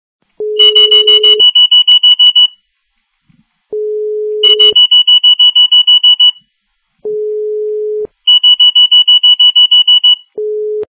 При прослушивании Alarm_Digital - Alarm_Digital качество понижено и присутствуют гудки.
Звук Alarm_Digital - Alarm_Digital